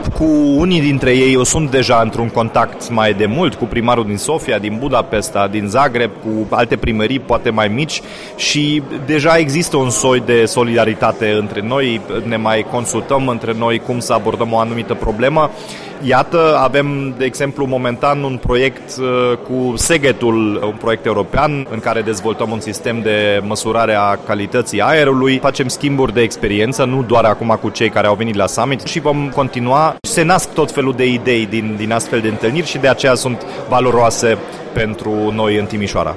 L-am întrebat imediat după emisiune pe  primarul Timișoarei, Dominic Fritz, cât vor simți locuitorii orașelor reprezentate la Summit roadele acestei întâlniri, dar și ale apartenenței  la Eurocities – rețeaua orașelor europene.